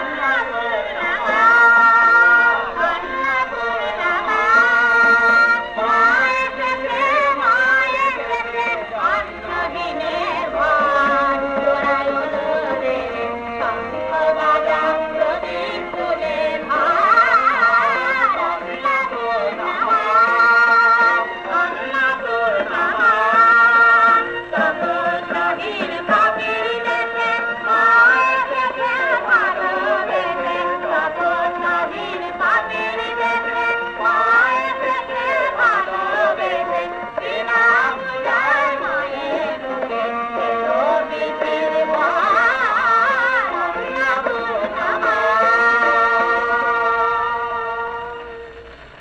• বিষয়াঙ্গ: ধর্মসঙ্গীত। সনাতন হিন্দু ধর্ম, শাক্ত, আগমনী।
• তাল: দাদরা
• গ্রহস্বর: পা